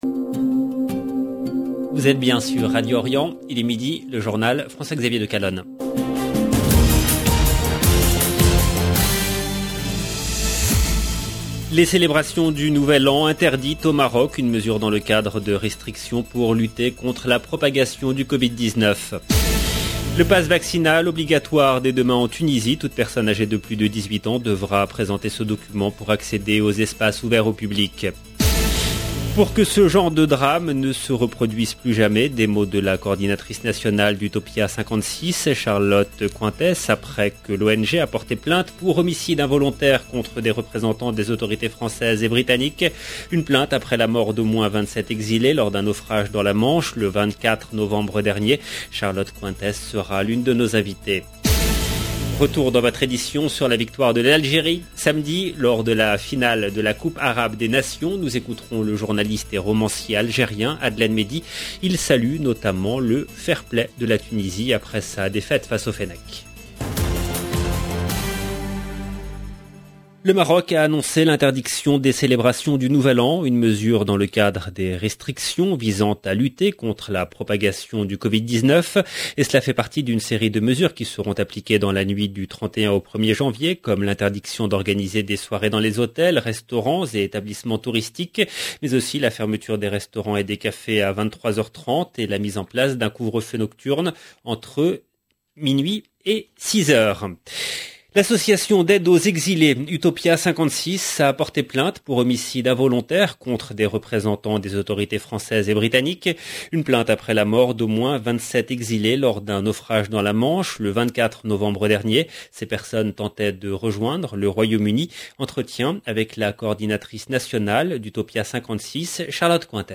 LE JOURNAL DE MIDI EN LANGUE FRANCAISE DU 21/12/21